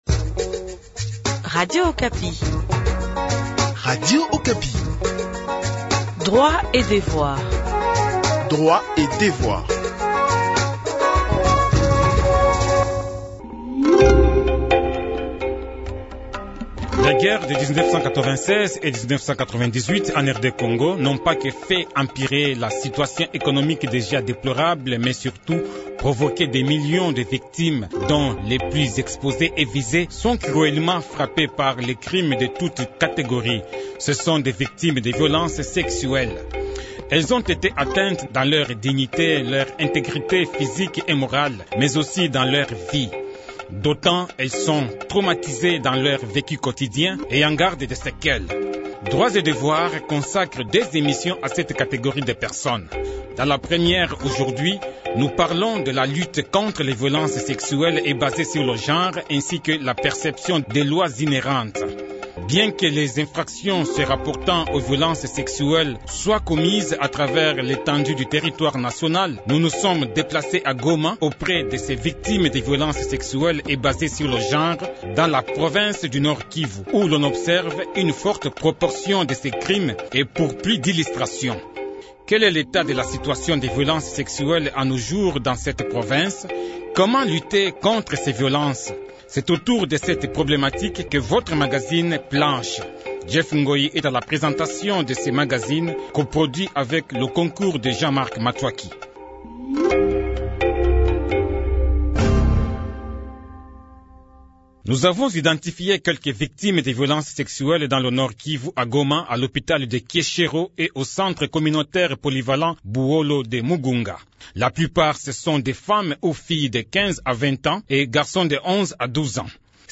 Bien que les infractions se rapportant aux violences sexuelles soient commises à travers l’étendue du territoire national, nous nous sommes déplacés à Goma auprès de ces victimes des violences sexuelles et basées sur le genre dans la province du Nord-Kivu où l’on observe une forte proportion de ces crimes et pour plus d’illustrations.